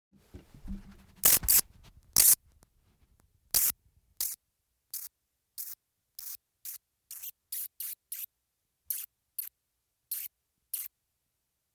Little Brown Bat
(Myotis lucifugus)
Little Brown Bat edit.mp3